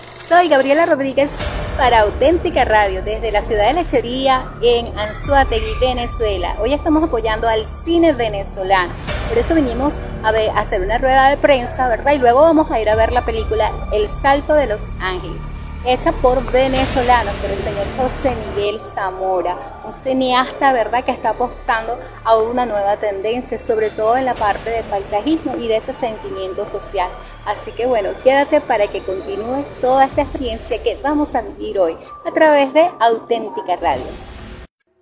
des d'Anzoátegui (Veneçuela) informa de la pel·lícula "El salto de los àngeles"